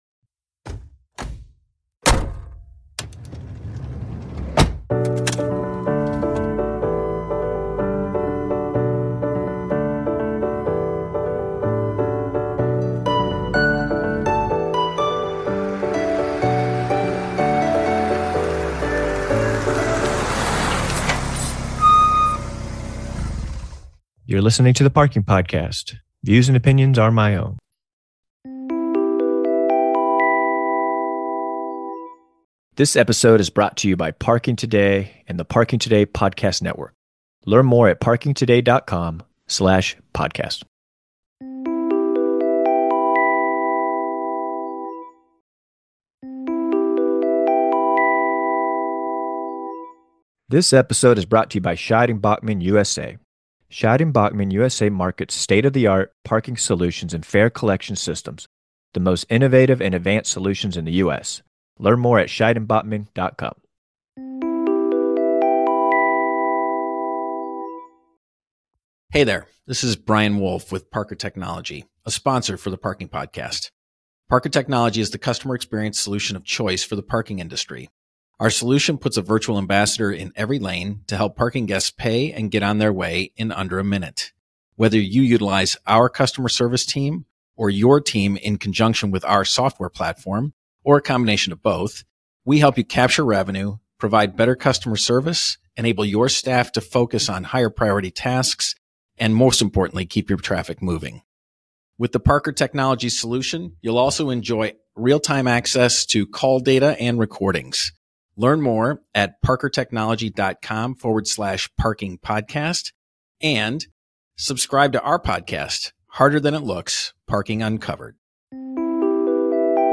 E135: Euphoric Misery - Audiobook Teaser (; 24 Jun 2025) | Padverb
Euphoric+Misery+Audiobook+Teaser.mp3